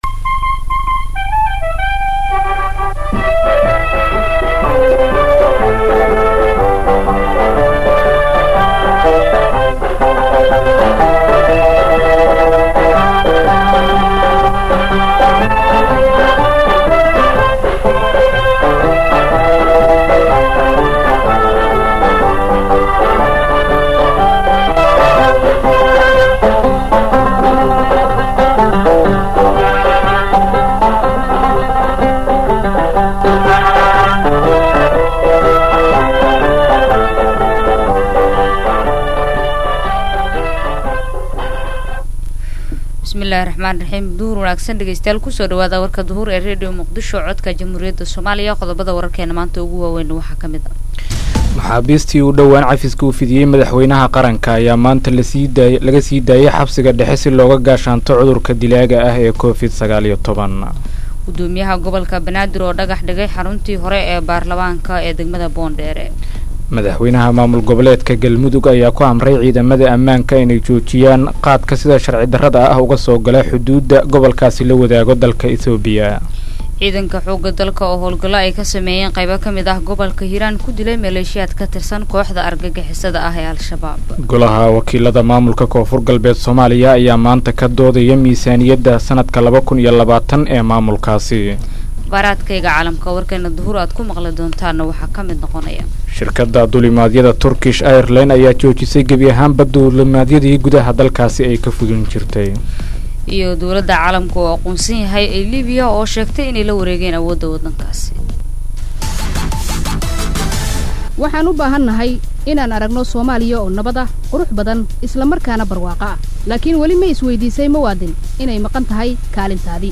Dhageyso warka duhur ee Radio Muqdisho 05.04.2020